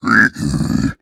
Minecraft Version Minecraft Version snapshot Latest Release | Latest Snapshot snapshot / assets / minecraft / sounds / mob / piglin / angry3.ogg Compare With Compare With Latest Release | Latest Snapshot
angry3.ogg